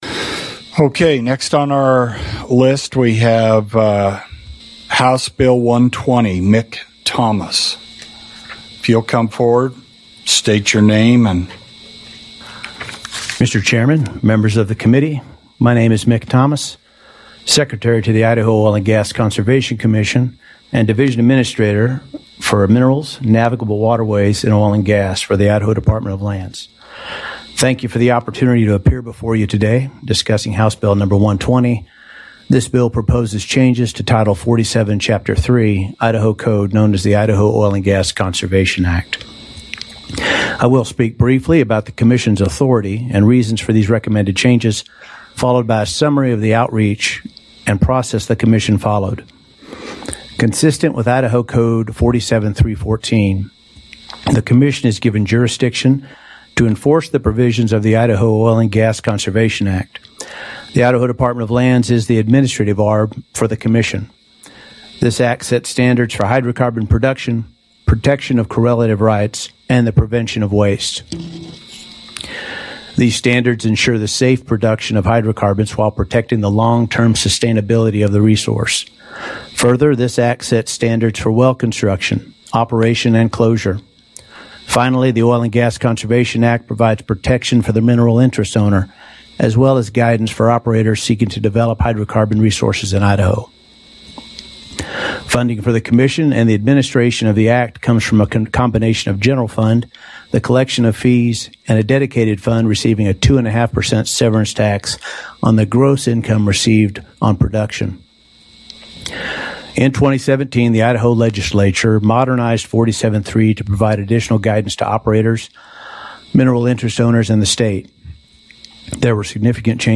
The Wednesday, March 22, 2023, Climate Justice Forum radio program, produced by regional, climate activists collective Wild Idaho Rising Tide (WIRT), features an Idaho Senate Resources and Environment committee hearing about House Bill 120 that would change oil and gas rules on extraction spacing units, oversight commission appointments, and forced lease terms for unwilling private mineral owners. We also share news, music, and reflections on the emerging spring season, a resisted and relocated Washington silicon smelter under construction in Tennessee, Washington derailments that killed an elk herd and spewed locomotive diesel on the oil train litigating Swinomish reservation, an Ohio attorney general lawsuit challenging Norfolk Southern hazardous train wreck impacts, and opposition by Colorado officials to proposed Utah crude oil-by-rail along the Colorado River.